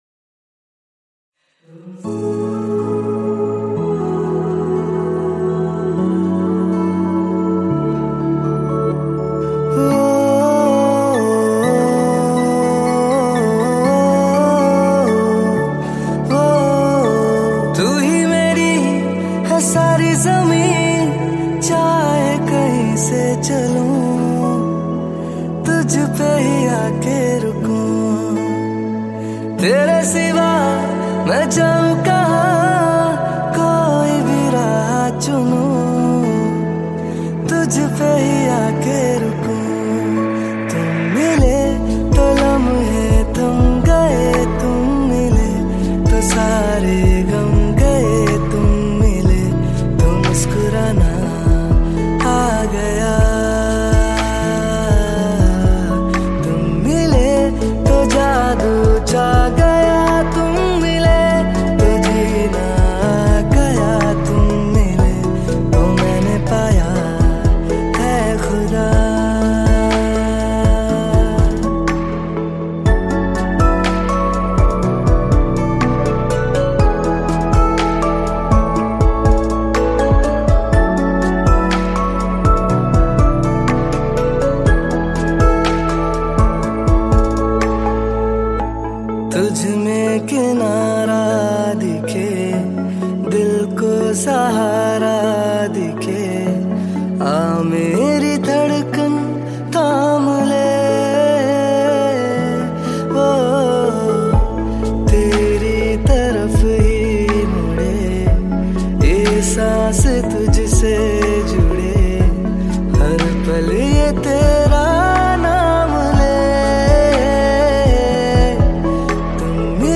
Category New Cover Mp3 Songs 2021 Singer(s